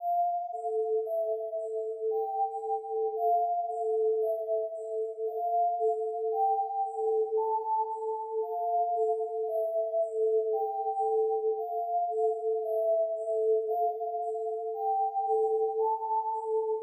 JAM01 PIANO ONLY SELFMADE HOT
标签： 114 bpm Hip Hop Loops Piano Loops 2.83 MB wav Key : Unknown
声道立体声